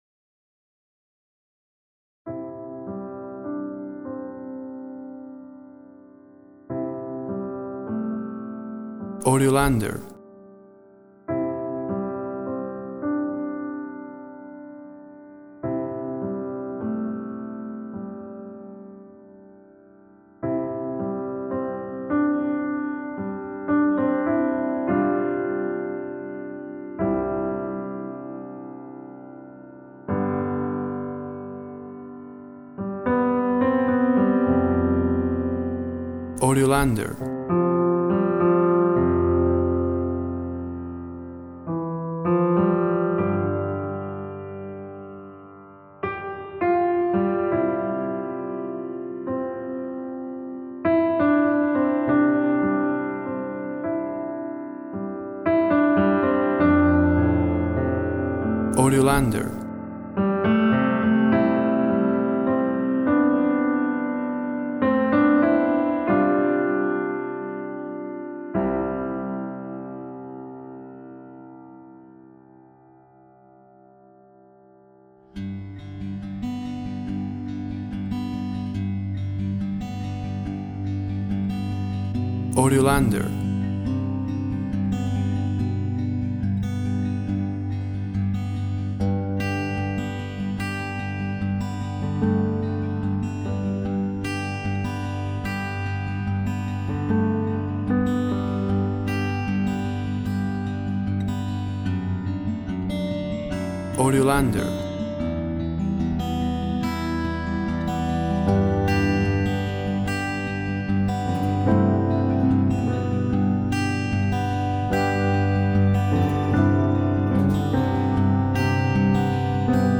Romantic song, love song.
Tempo (BPM) 55/75